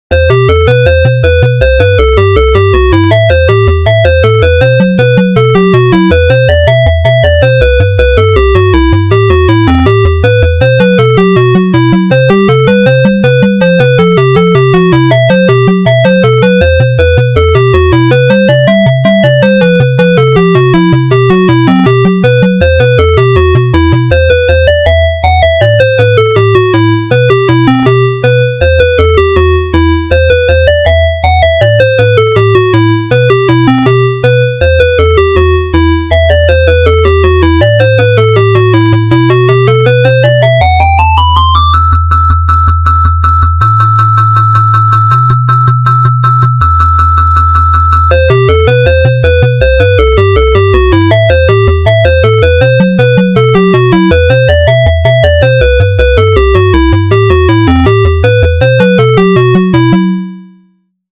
レトロな感じの戦闘音楽です。
BPM160
レトロ